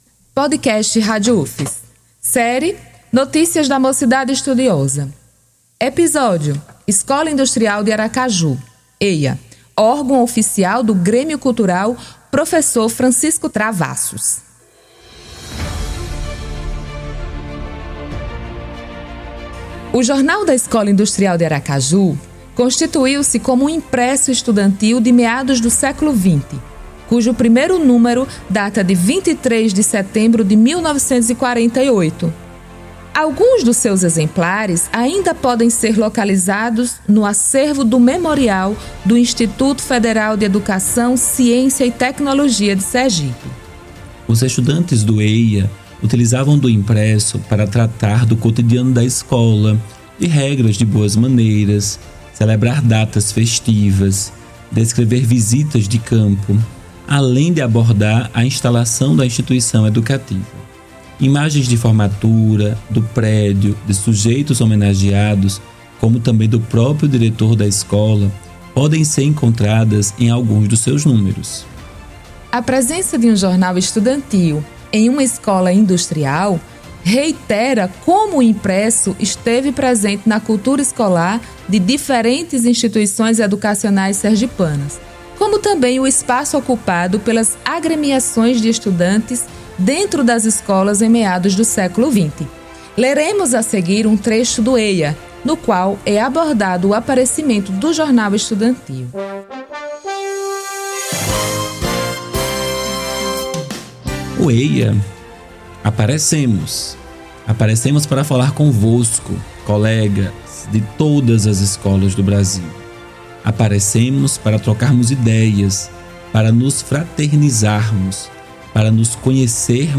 [Locução de]